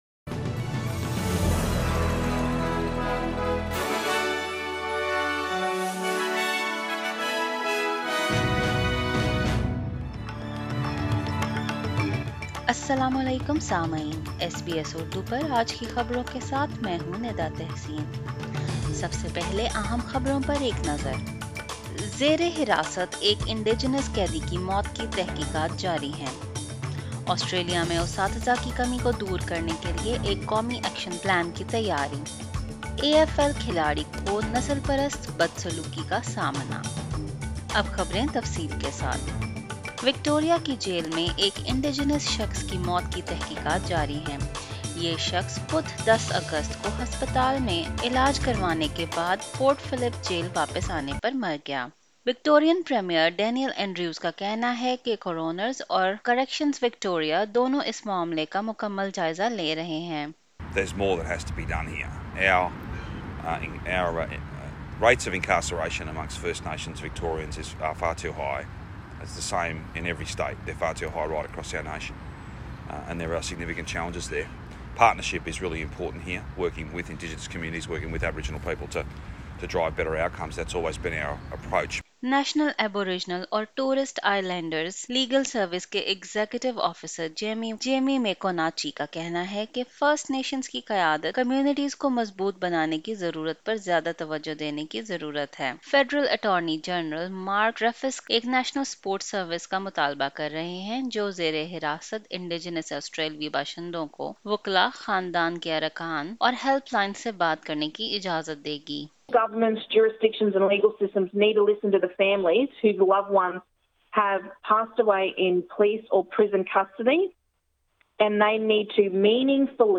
In this bulletin, An investigation underway into an Indigenous death in custody. A national action plan to address Australia's teacher shortage. An AFL player calls out racist online abuse.